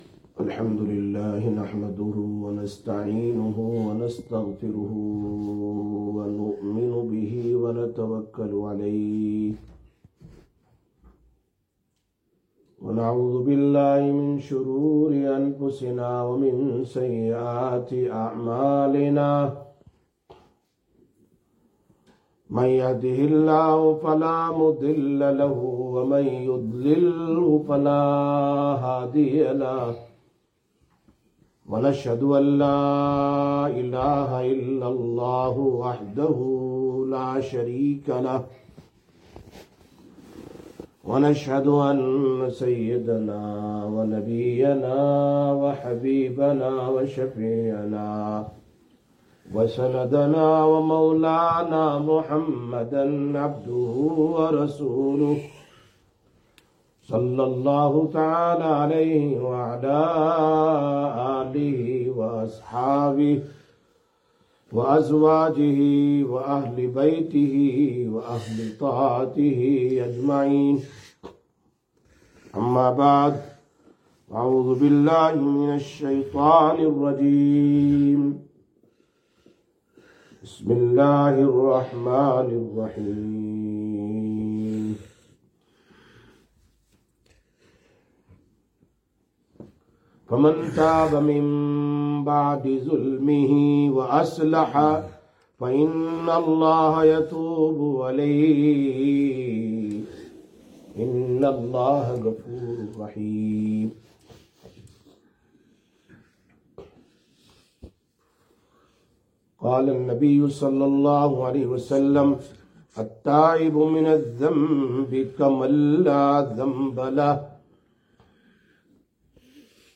21/03/2025 Jumma Bayan, Masjid Quba